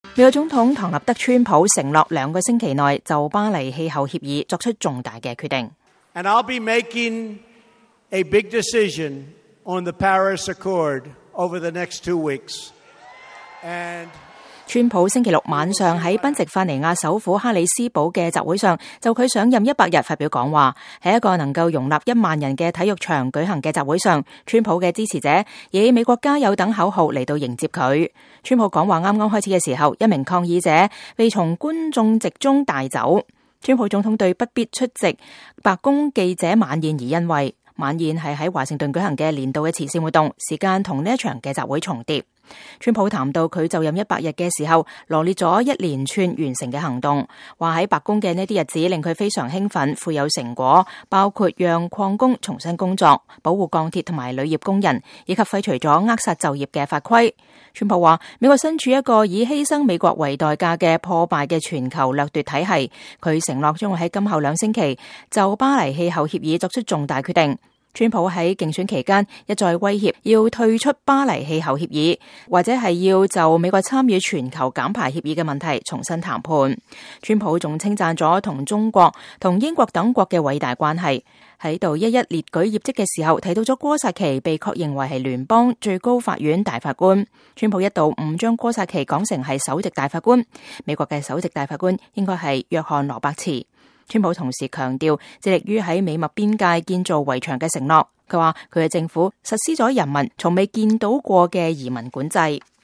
美國總統唐納德·川普承諾兩星期內就巴黎氣候協議做出“重大決定”。川普星期六晚上在賓夕法尼亞首府哈里斯堡的一場集會上就他上任100天發表講話。在一個能容納一萬人的體育場舉行的集會上，川普的支持者以“美國加油！”等口號來迎接他。